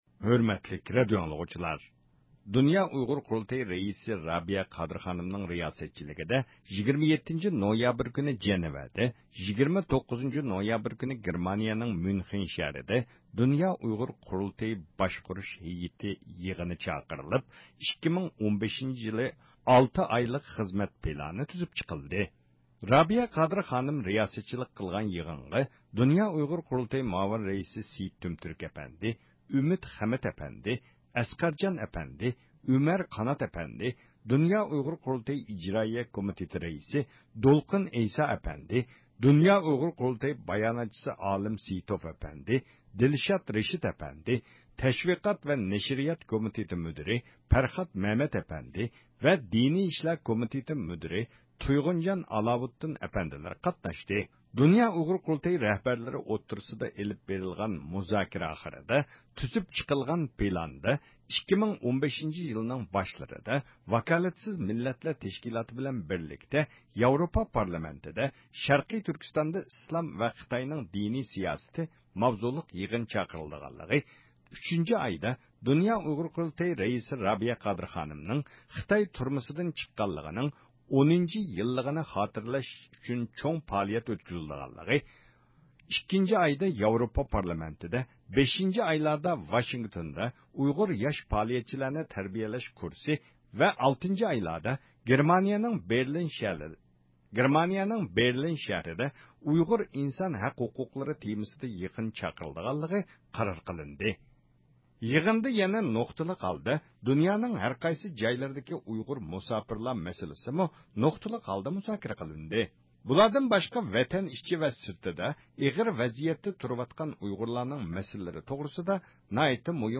2014-يىلى 27-نويابىر، ميۇنخېن.